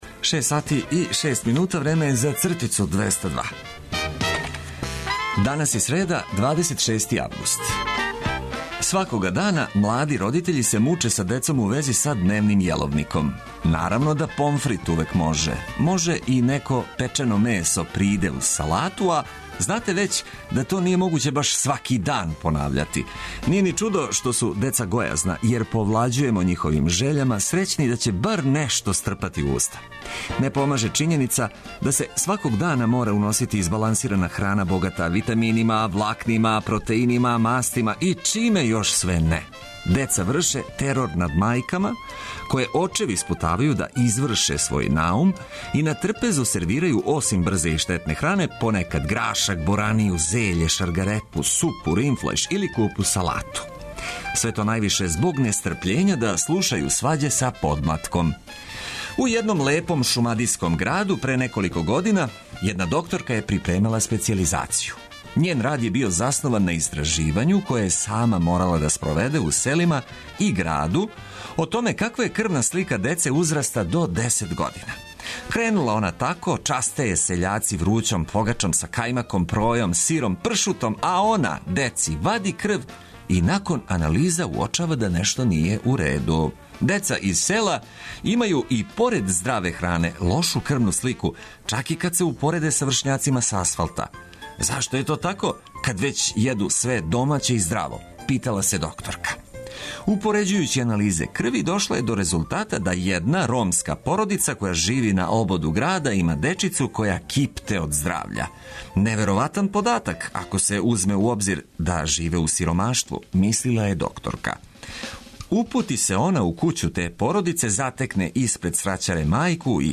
Започнимо последњу среду у августу уз осмех и ведру музику, добро расположени упркос свему.